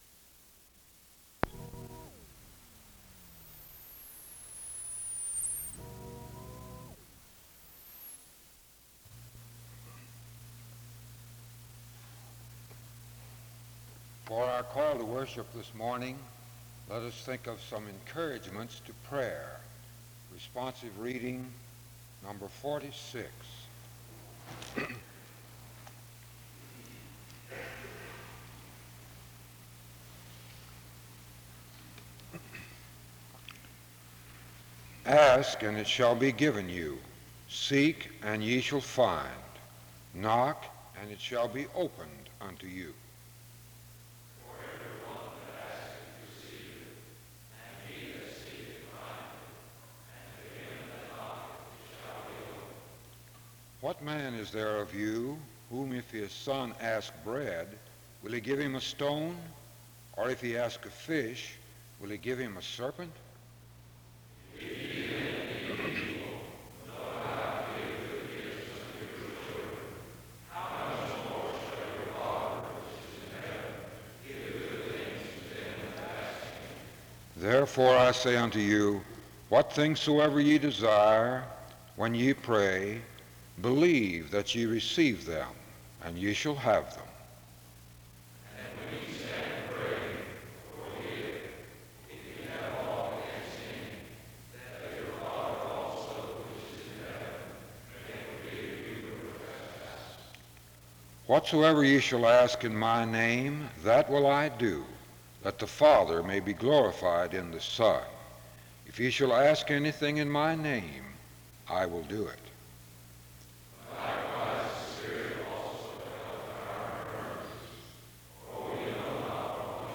The service opens with encouragements to prayer from responsive reading #46 (00:34-02:33), followed by a public reading of Psalm 136:1 (02:44-02:51) and an opening prayer (02:52-04:57).